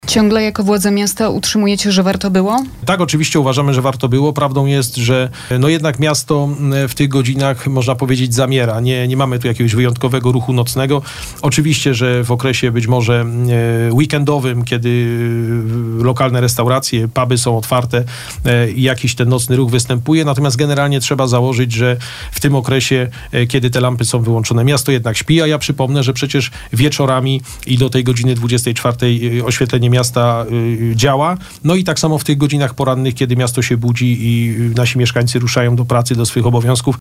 Zatem nie jest to pełna oszczędność – mówił na naszej antenie Adam Ruśniak, zastępca prezydenta Bielska-Białej.